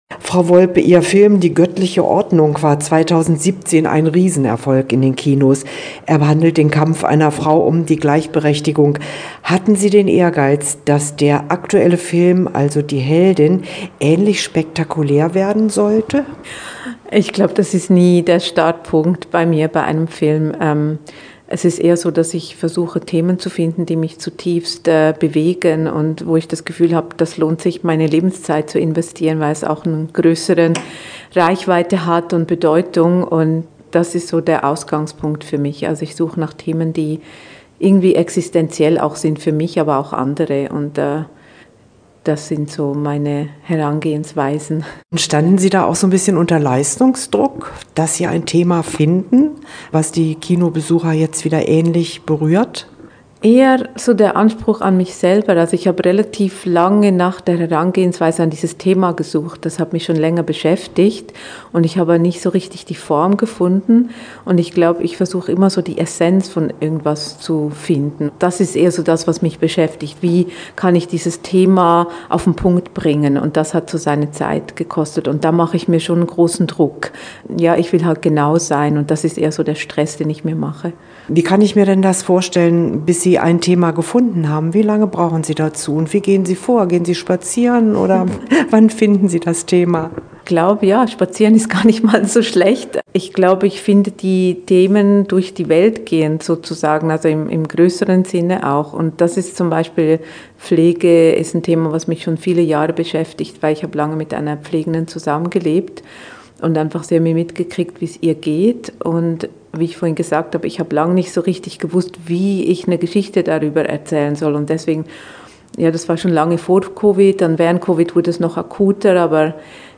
Interview-Heldin-Volpe.mp3